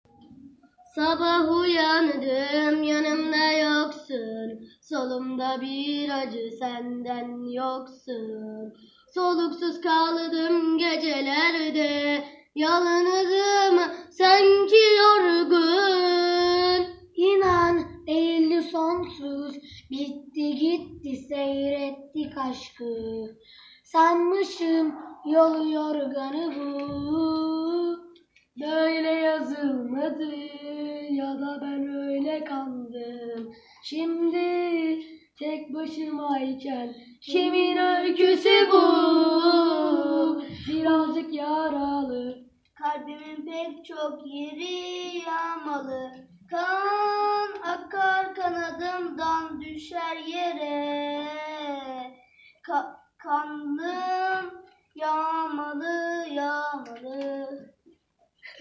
Giho Şarkı Yarışması Albümü